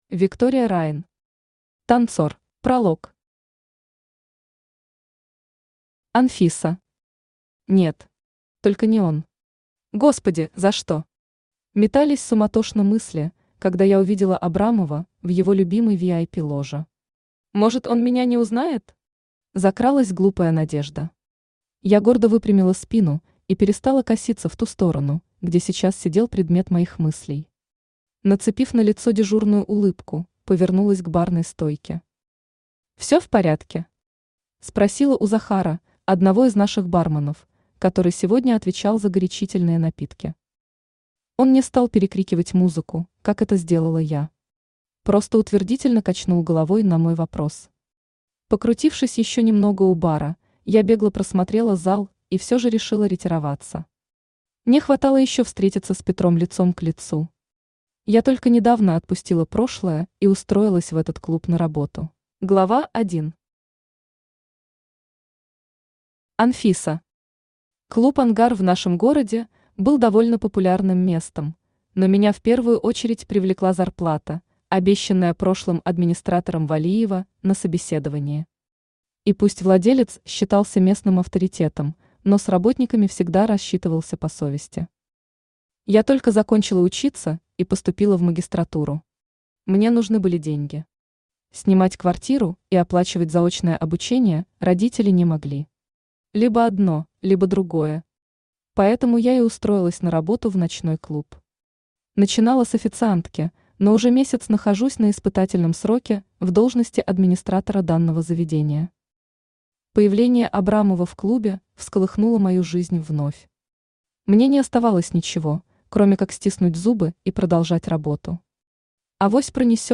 Aудиокнига Танцор Автор Виктория Райн Читает аудиокнигу Авточтец ЛитРес. Прослушать и бесплатно скачать фрагмент аудиокниги